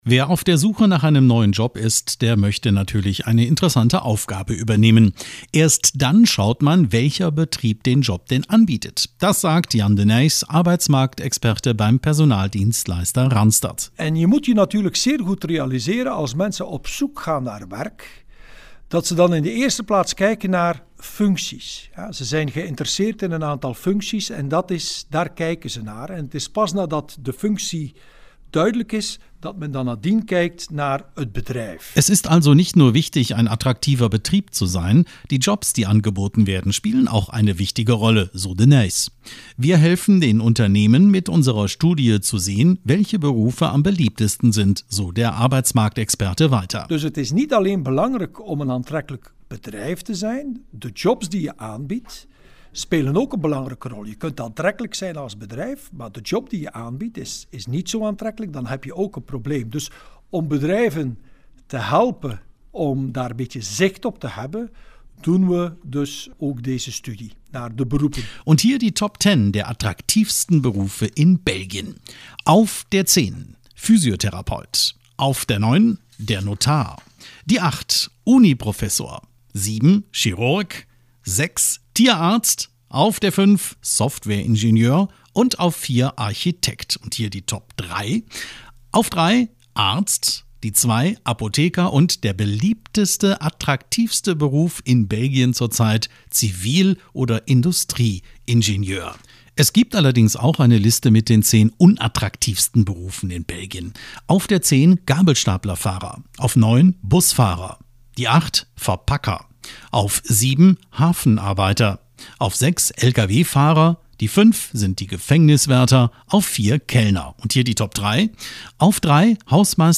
Seit dem Jahr 2000 führt der Personaldienstleister Randstad in Belgien Umfragen zu den beliebtesten Berufen durch. Die Ergebnisse der neusten Studie wurden jetzt in Brüssel vorgestellt. Das Interview